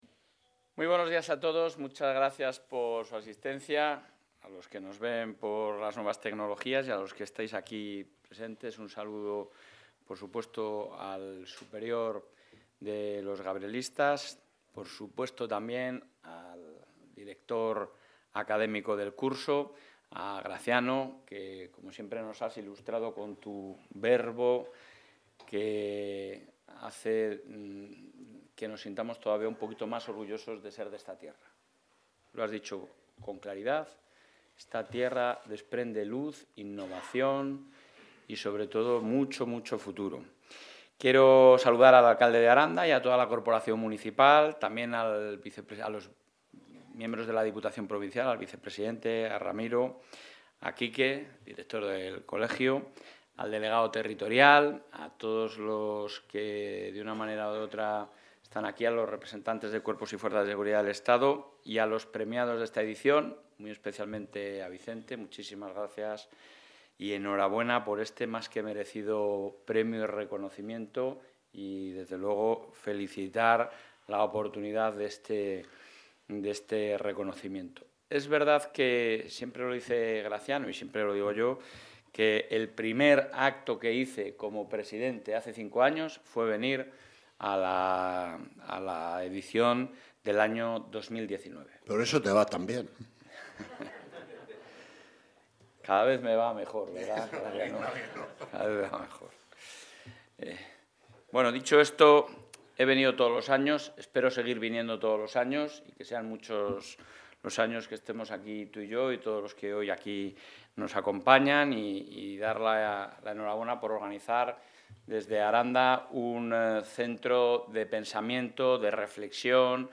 Intervención del presidente de la Junta.
El presidente de la Junta de Castilla y León, Alfonso Fernández Mañueco, ha participado hoy, en Aranda de Duero (Burgos), en la XII Edición del Curso Universitario 'Prensa y Poder', en el que se ha abordado la importancia de la actividad económica y el emprendimiento empresarial en el panorama actual.